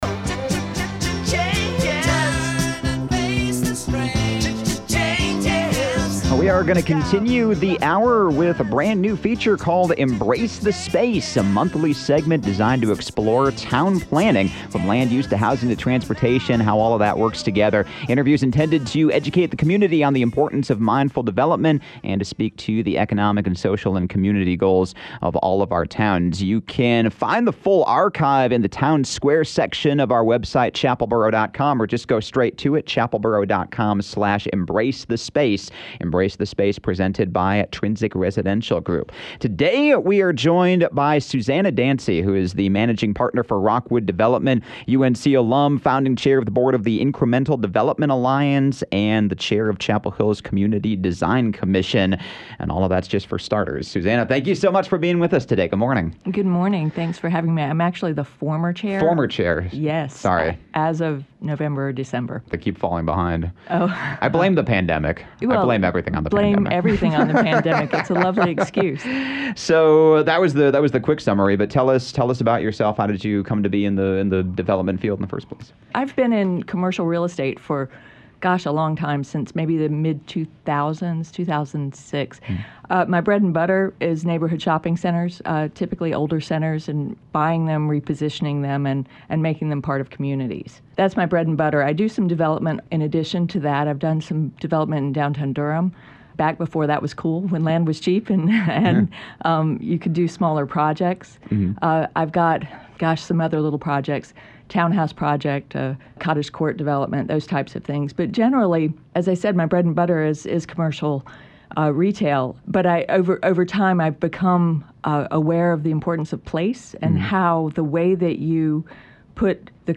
A monthly segment presented by Trinsic Residential Group, “Embrace The Space” features conversations with experts and professionals in town planning, discussing how differing concepts — from land use and housing to transportation and beyond — all come together to create a community that works, and works for people.